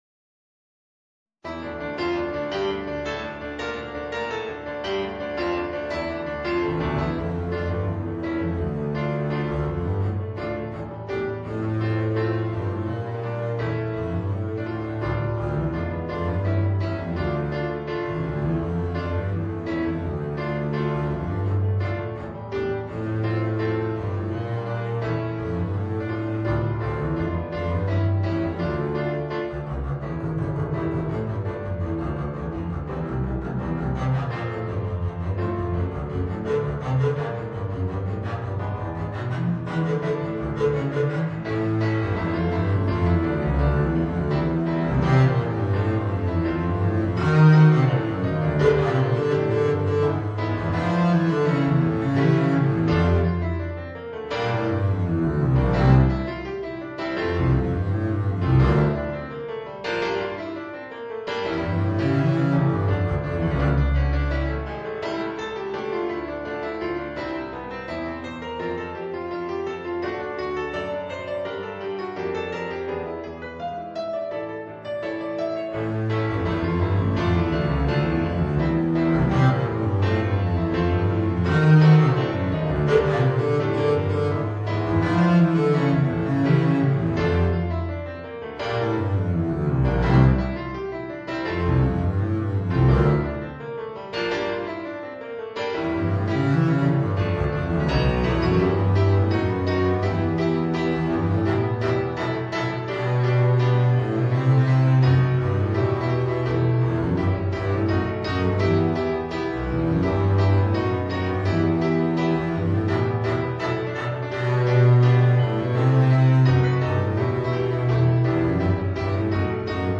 Voicing: Contrabass and Piano